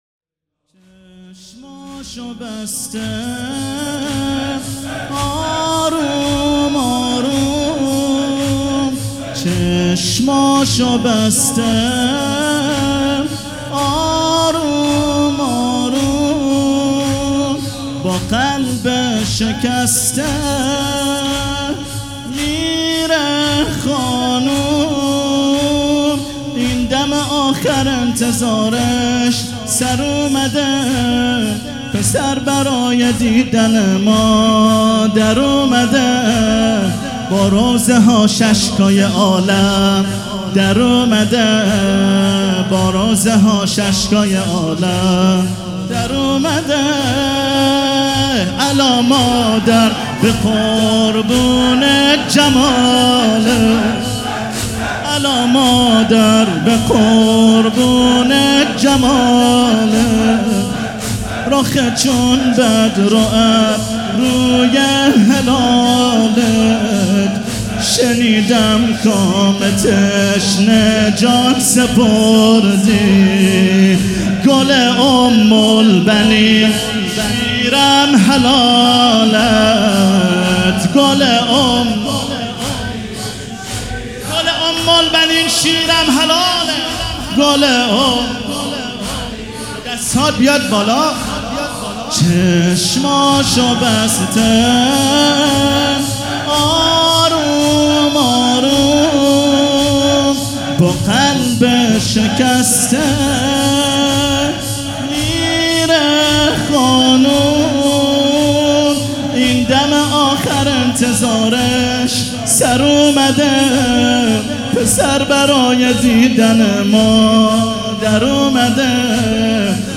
حسینیه ریحانة‌الحسین (س)
سبک اثــر زمینه